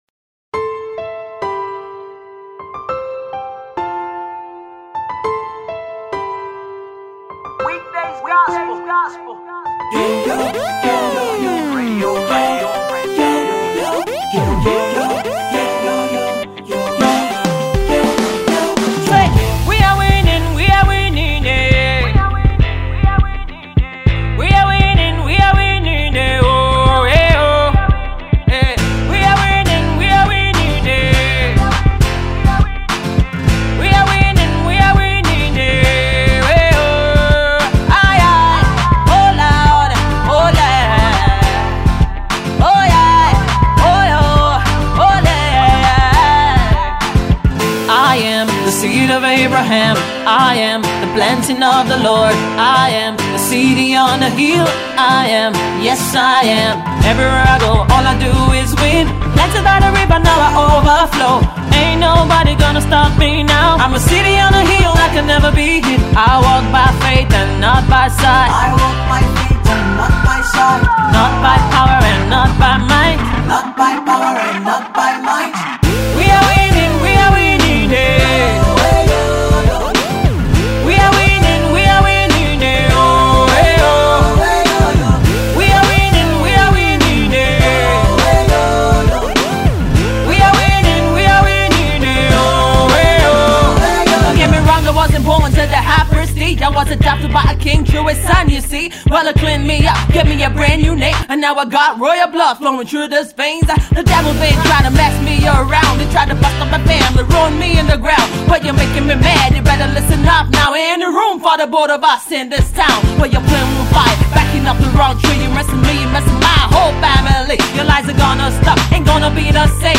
Popular Gospel Artist